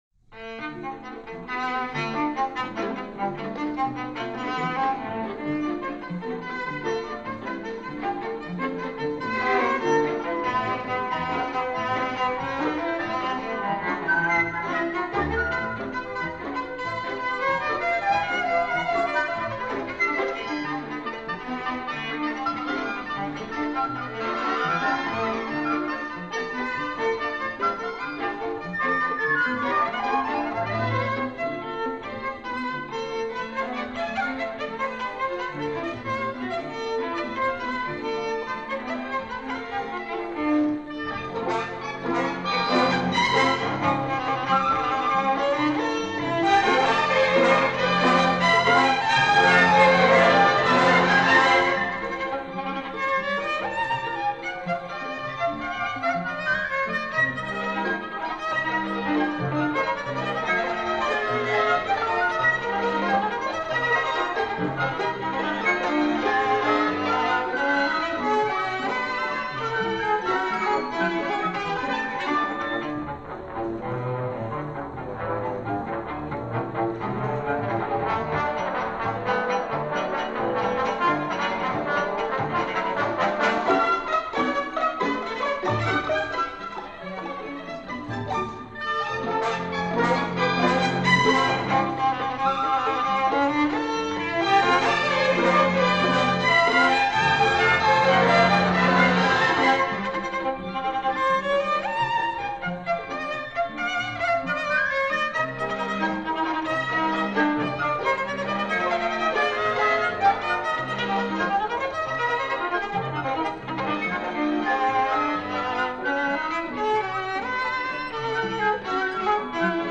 neo-classical